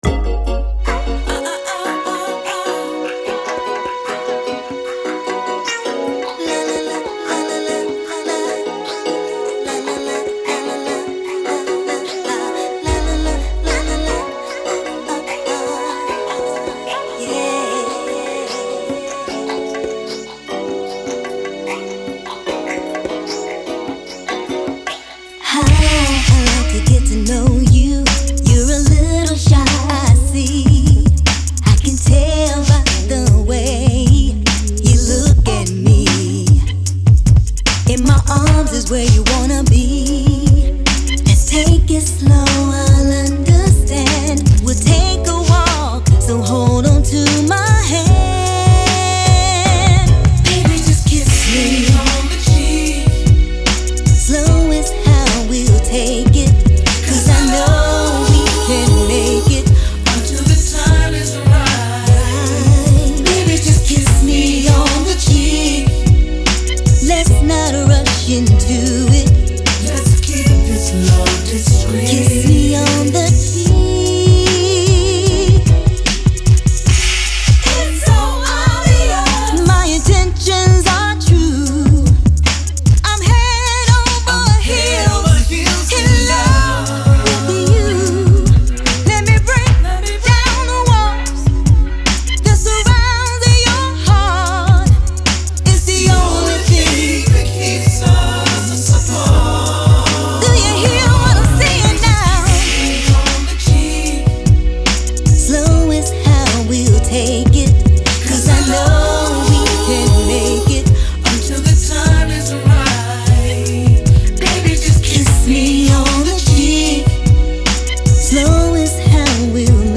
RnB
sassy r&b song
blazing guitar